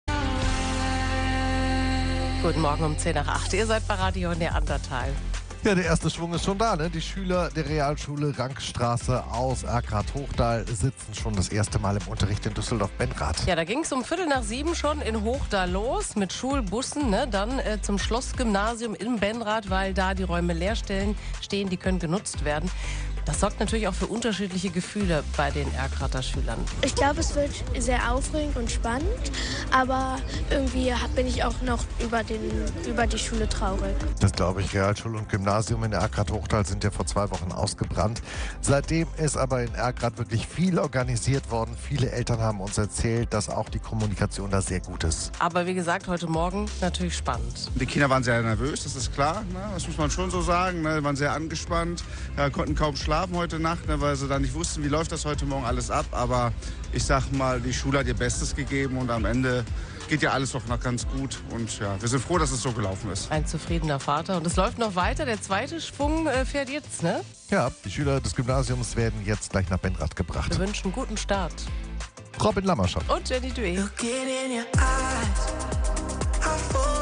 Wir waren heute live für Euch dabei!